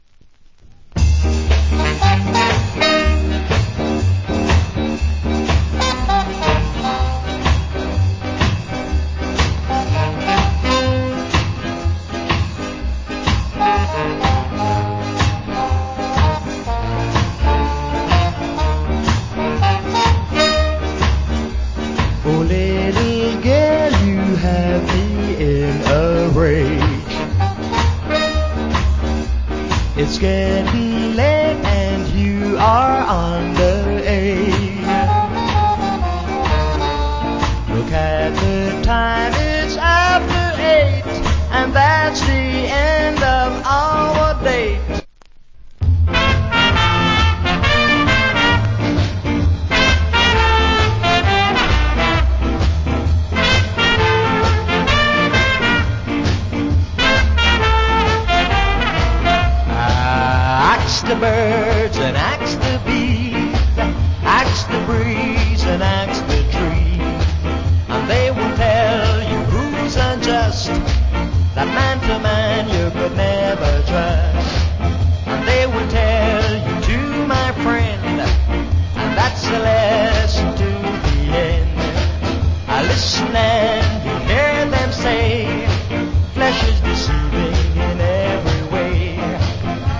Wiched Ska Vocal.